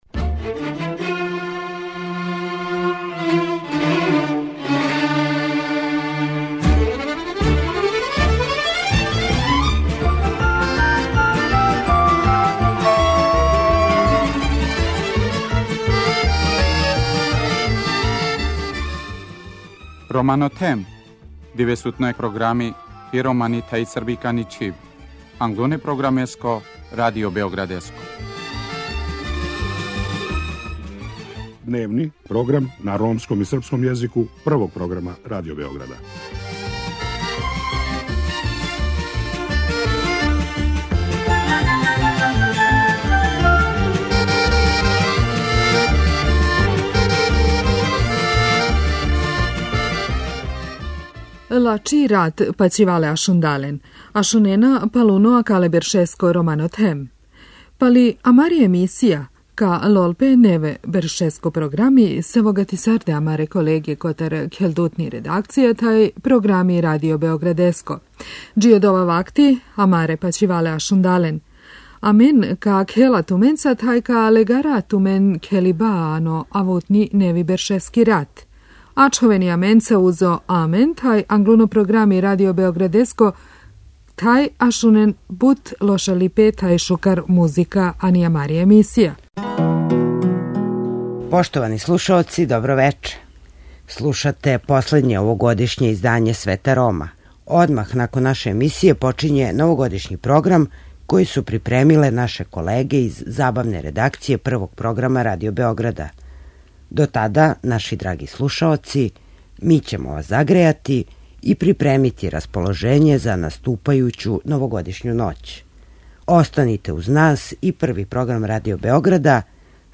У последњем овогодишњем издању Света Рома наша редакција ће вас загрејати и припремити за наступајућу новогодишњу ноћ. У педесет минута емисије биће пуно забаве и још боље музике.